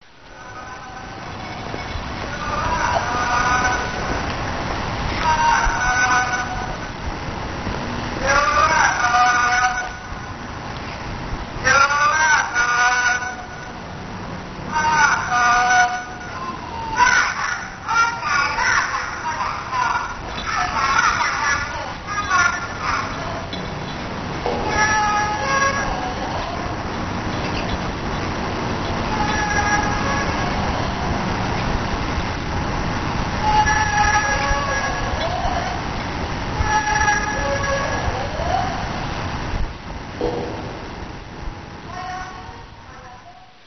If you've been to Taiwan, you've probably heard this sound and wondered what the fxxx it was.
ZongziTruck.mp2